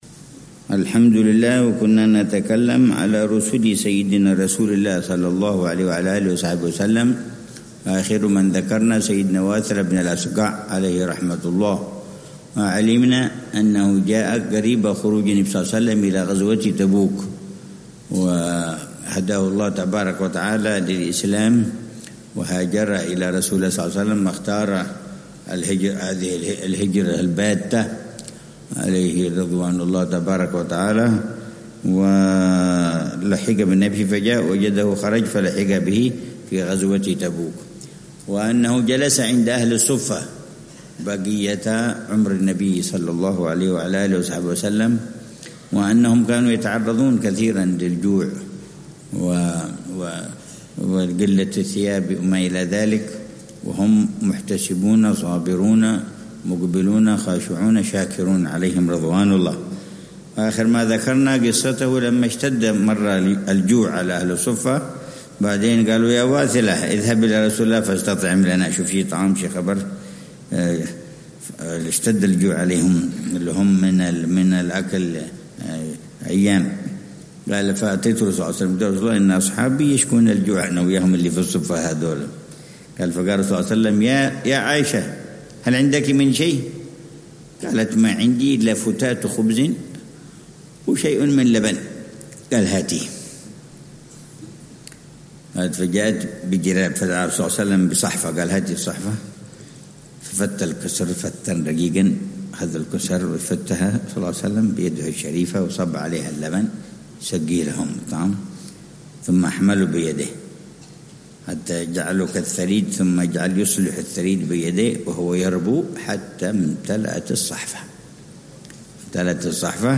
من دروس السيرة النبوية التي يلقيها العلامة الحبيب عمر بن محمد بن حفيظ، ضمن دروس الدورة التعليمية الحادية والثلاثين بدار المصطفى بتريم للدراسات